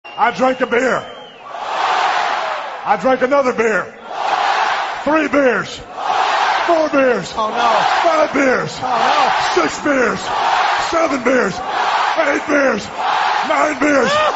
Stone Cold Steve Austin Saying Drink Some Beer